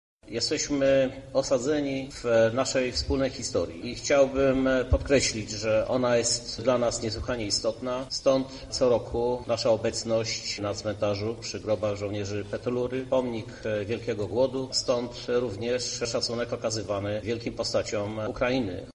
Jesteśmy częścią Europy i w tym wymiarze traktujemy naszą aktywność — mówi prezydent Krzysztof Żuk jednocześnie podkreślając wagę wspólnej przeszłości: